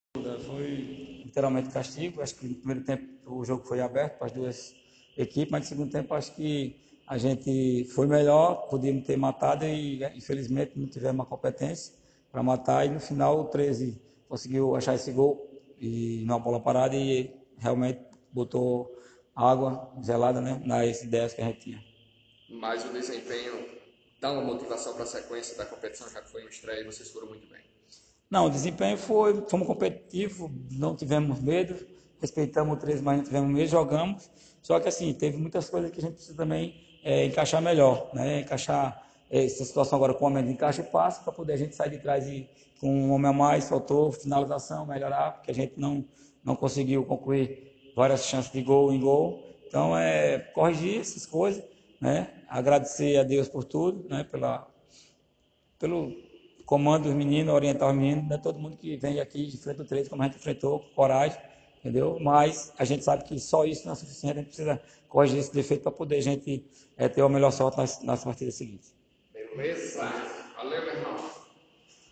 Seguindo a política de ouvir quem vence e quem perde, o Soesporte Podcast esteve nos vestiários do CSP depois da derrota de 2 a 1 do Tigre para o Treze, no estádio Amigão.